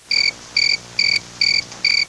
Cricket chirping
CRICKET.wav